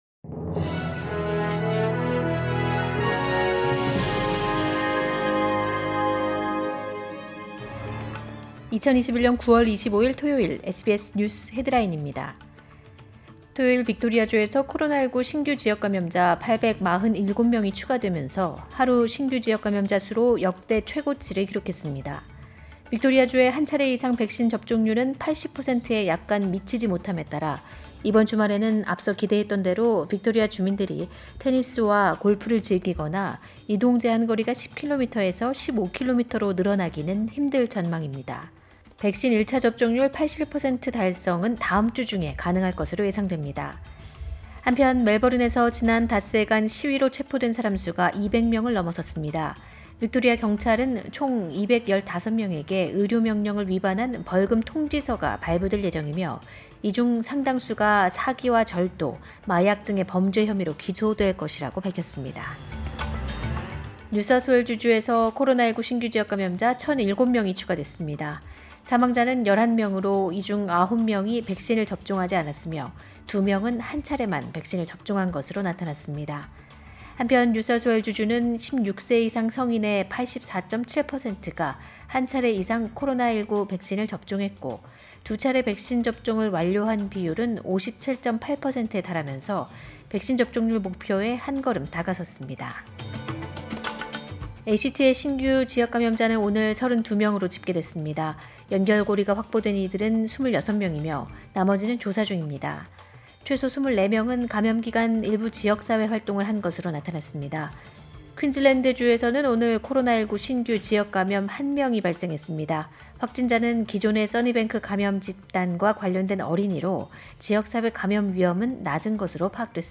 2021년 9월 25일 토요일 SBS 뉴스 헤드라인입니다.